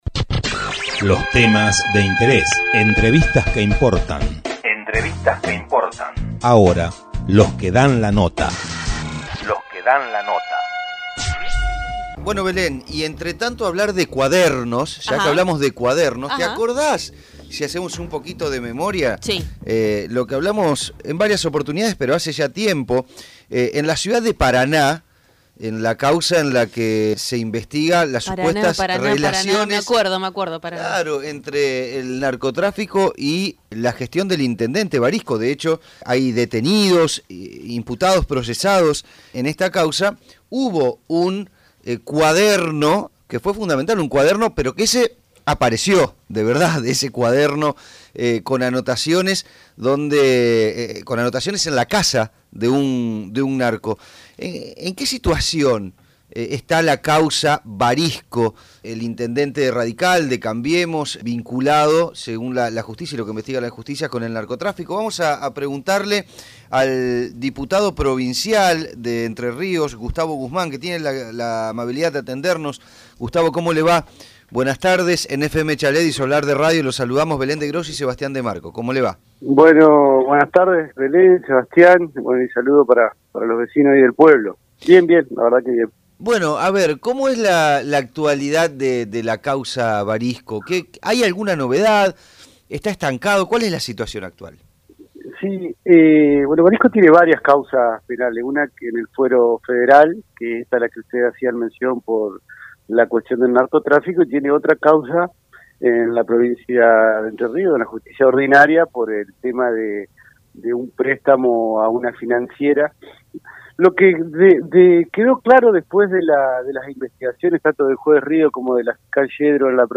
Tren Urbano se comunicó con el diputado provincial de Entre Ríos, Gustavo Guzmán (Frente para la Victoria), para conversar sobre la actualidad de la Causa Varisco.